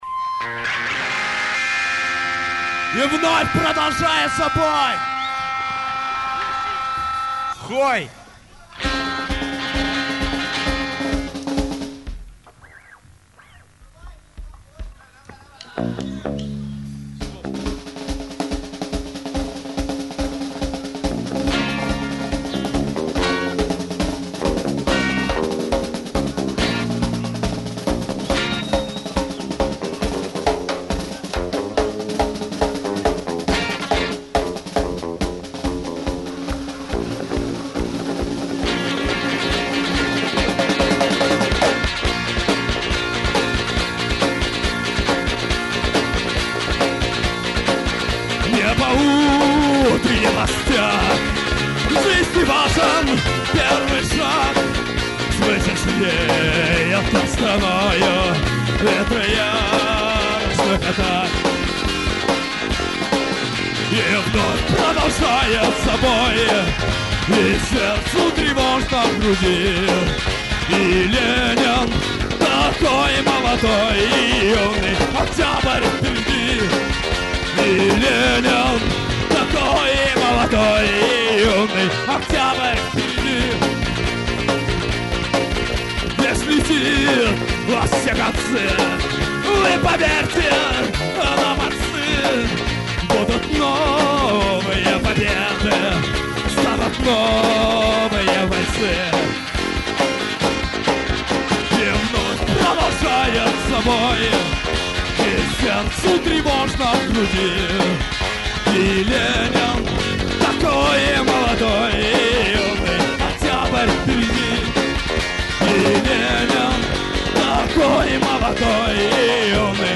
Концерт 18.02.00 в ЦеЛКе в Двинске.